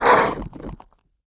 damege_act_1.wav